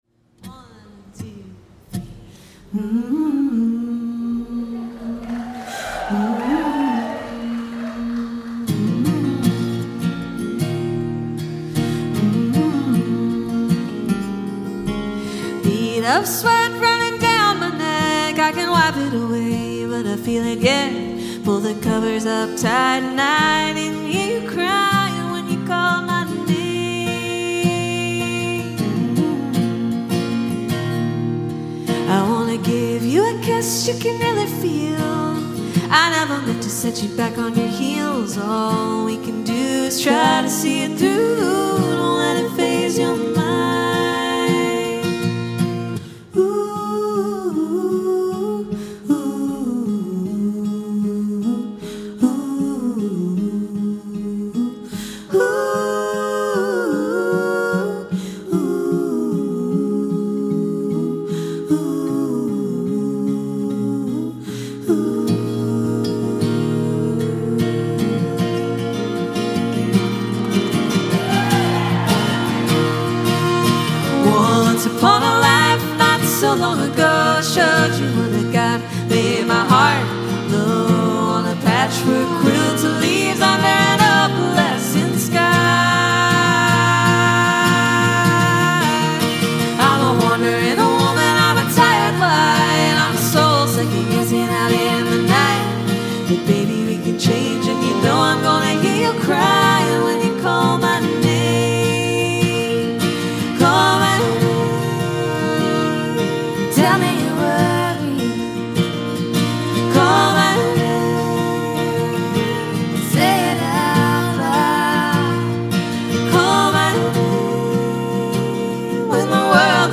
smooth and engaging tune